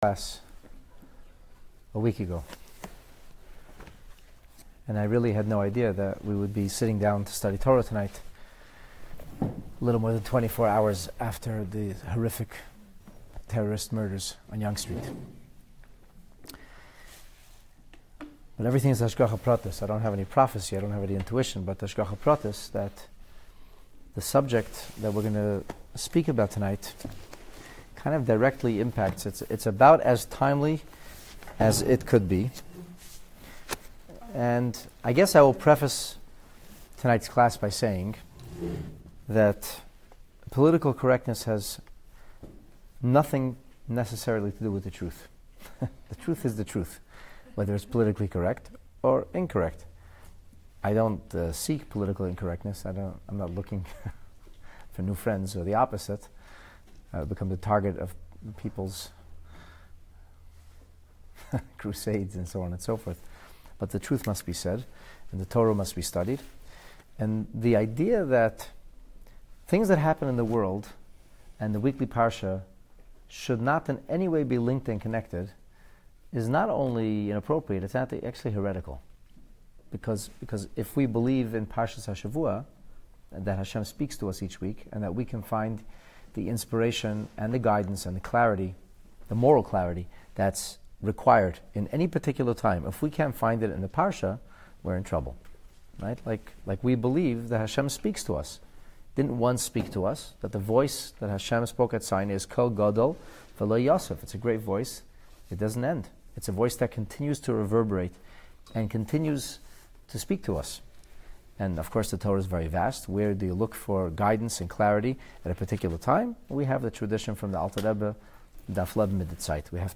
Presented in Toronto on the day after a horrific vehicular terrorist attack kills 10 innocent pedestrians on the peaceful city streets. This profound rumination about the extent of responsibility for others carefully scrutinizes the Oral Torah’s sacred teachings that accompany the scripture; concluding with a climatic analysis of Rashi’s unique commentary as illuminated by the Rebbe.